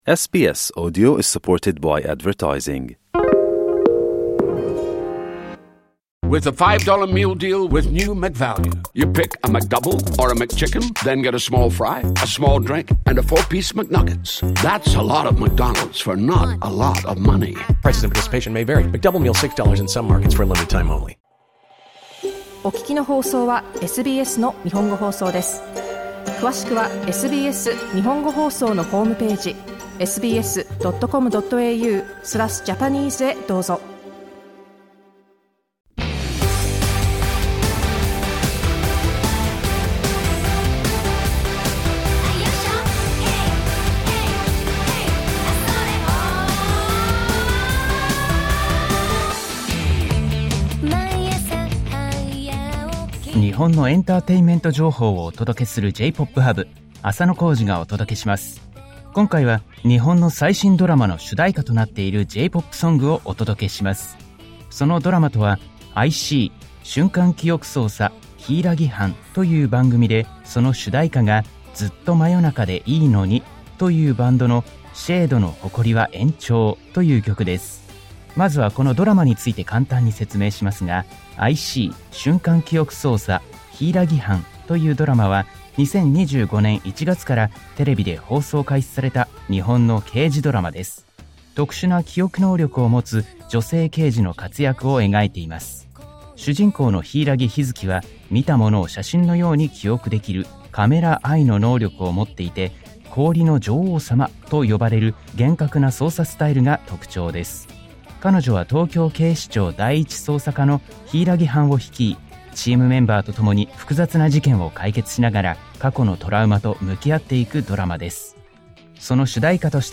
SBS Japanese's music segment J-Pop Hub is broadcast on Thursdays.